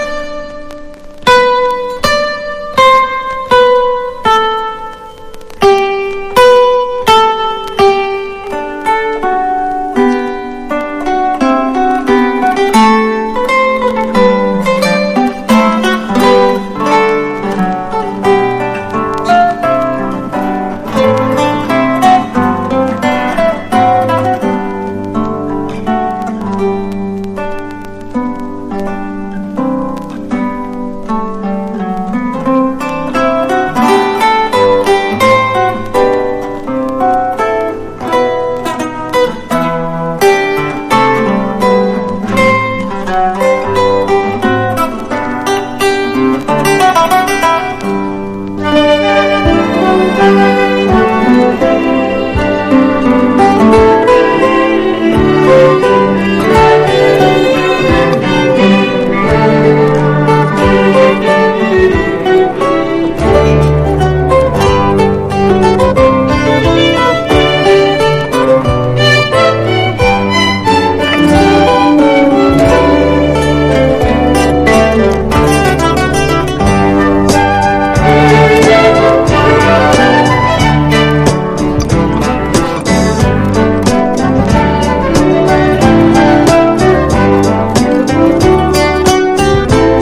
PSYCHEDELIC / JAZZ / PROGRESSIVE